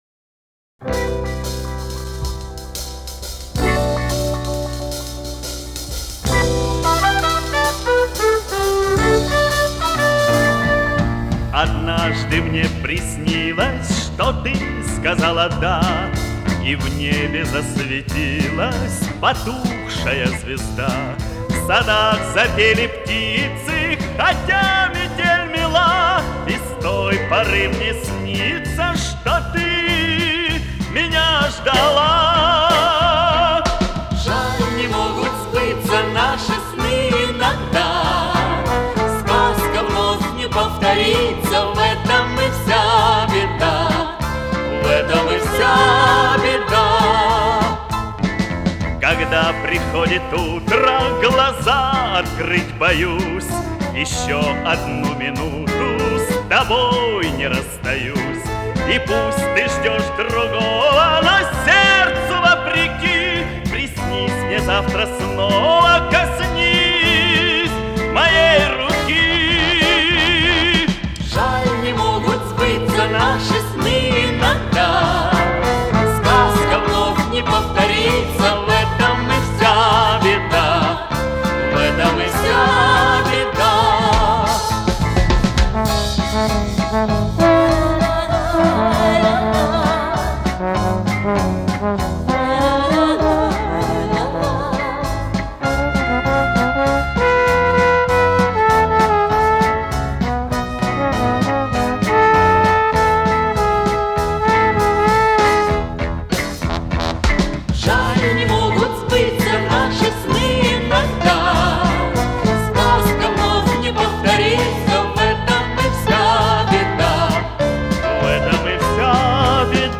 Запись с винила.